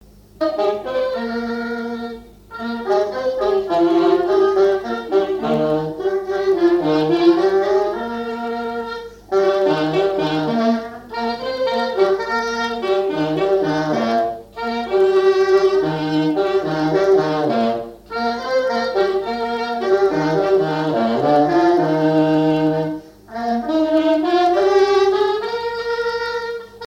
trompette
saxophone
Basse
clarinette
circonstance : fiançaille, noce
Pièce musicale inédite